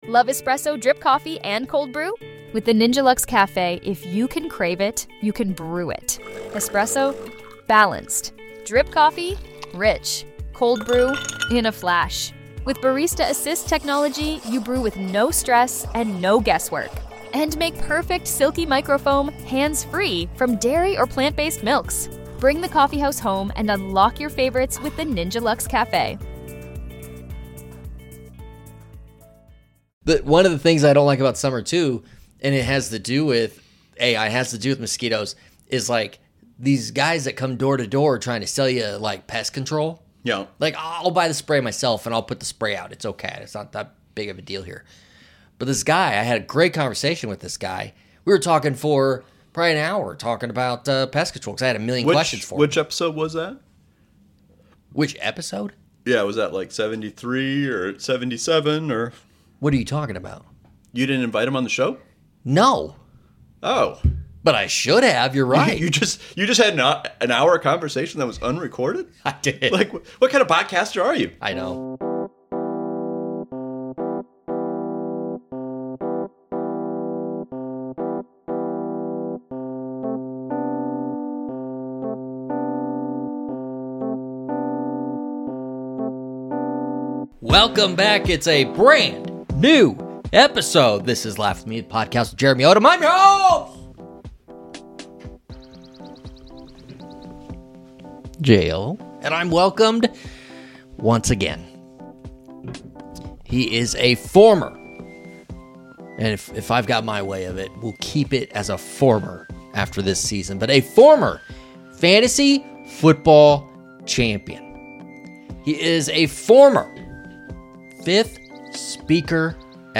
Comedy, Stand-up